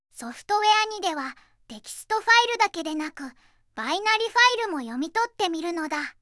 • クレジット: VOICEVOX:ずんだもん